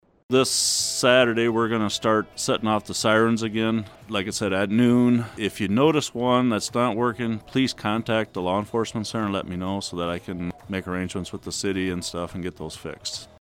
Humboldt, IA-Humboldt County is testing their storm sirens this weekend. Here’s Humboldt County Sheriff Dean Kruger with the details.